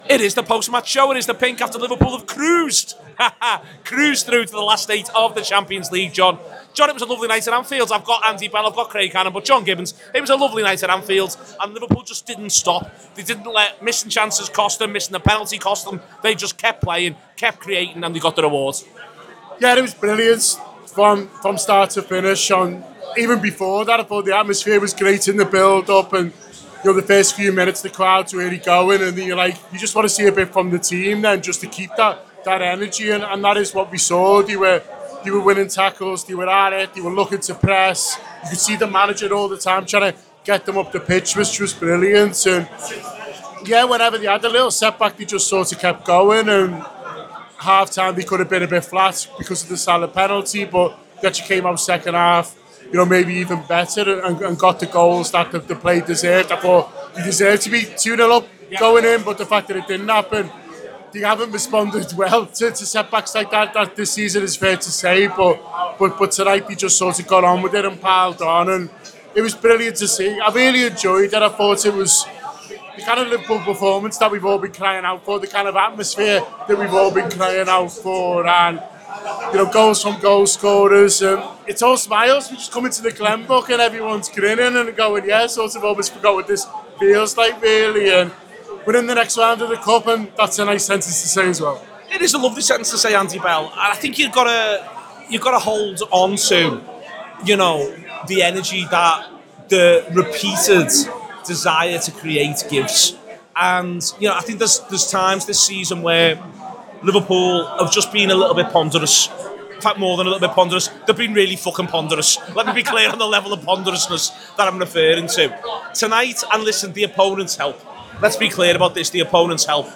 Below is a clip from the show – subscribe to The Anfield Wrap for more reaction to Liverpool 4-0 Galatasaray